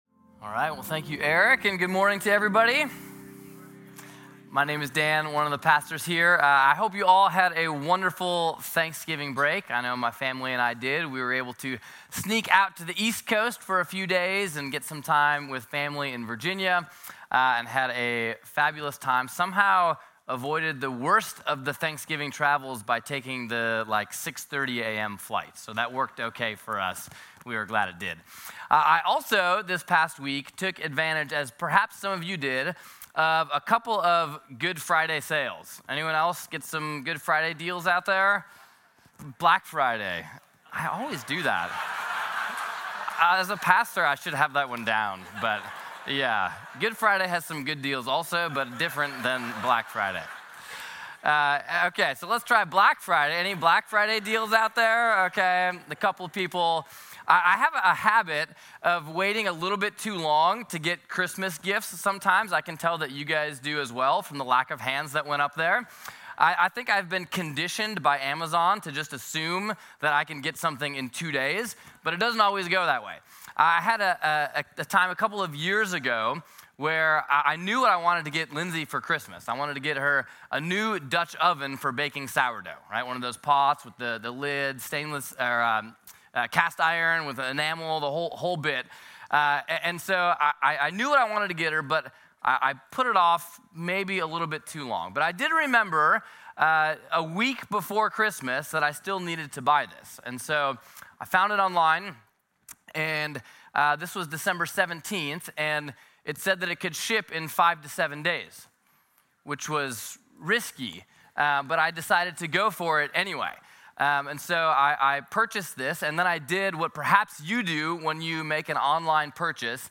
Sermons - Peninsula Bible Church